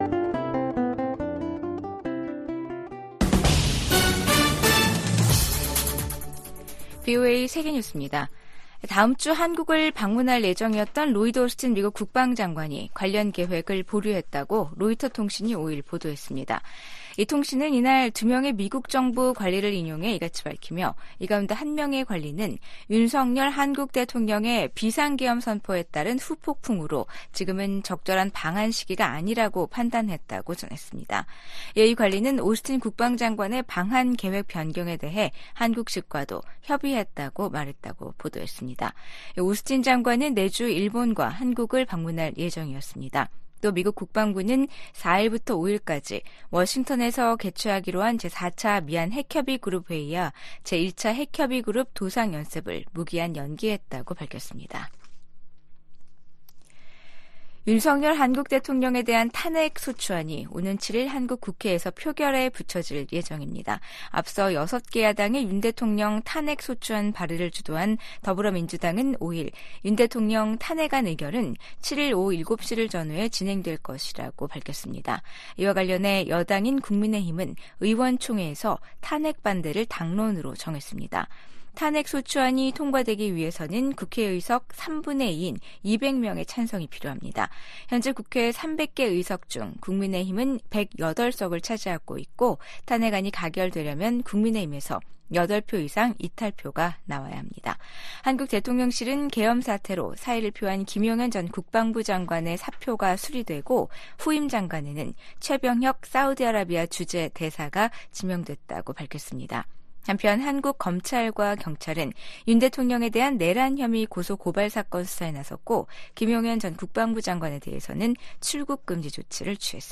VOA 한국어 아침 뉴스 프로그램 '워싱턴 뉴스 광장'입니다. 한국 6개 야당이 비상계엄 선포와 관련해 발의한 윤석열 대통령 탄핵소추안이 7일 국회에서 표결에 부쳐질 예정입니다. 미국 국무장관이 한국은 전 세계에서 가장 모범적인 민주주의 국가 중 하나라면서 윤석열 한국 대통령의 비상계엄 해제 결정을 환영했습니다.